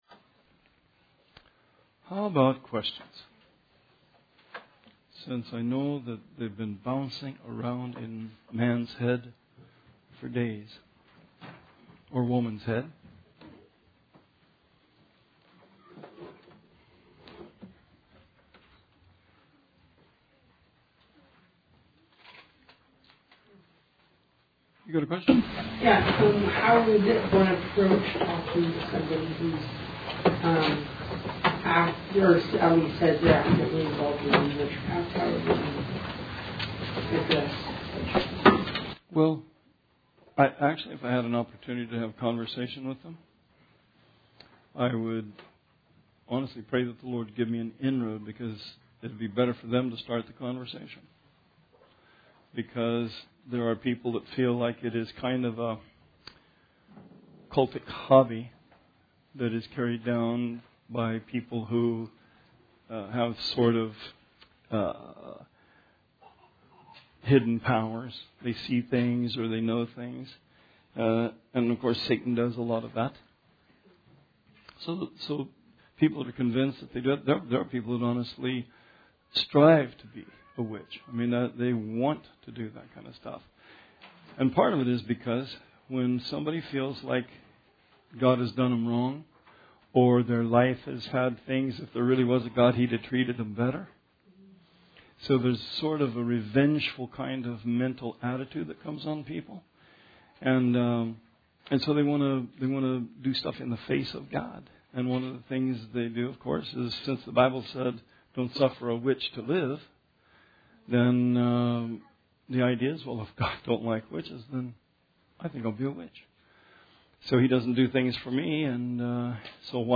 Bible Study 3/6/19